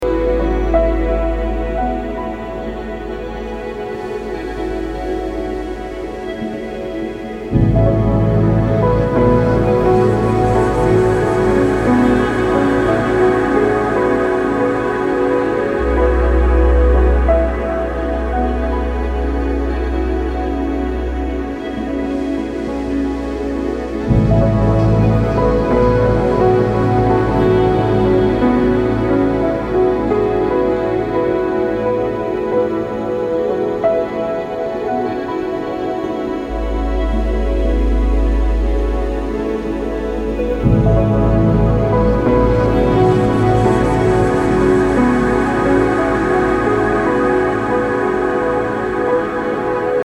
Ambient, Drone >
Post Classical >